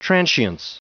Prononciation du mot transience en anglais (fichier audio)
Prononciation du mot : transience